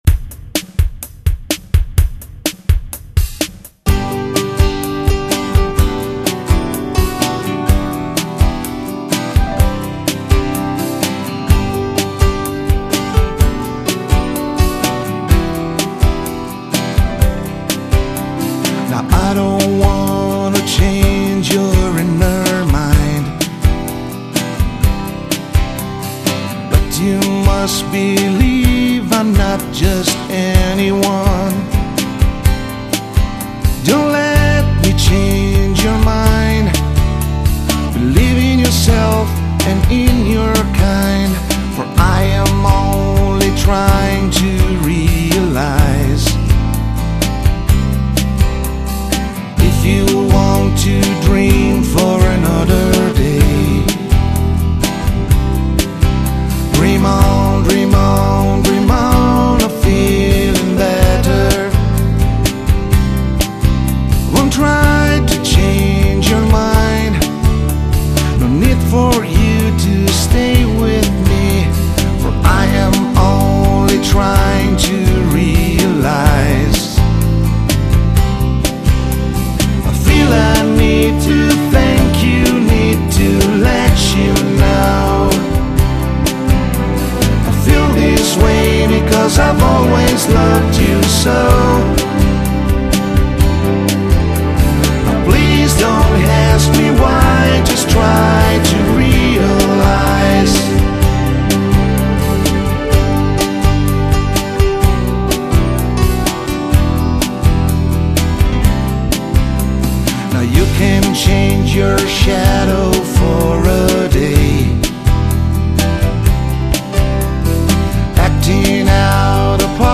Vocals - Bass - Acoustic Guitar
Electric Guitars
Keyboards and Drums Programming
Background Vocals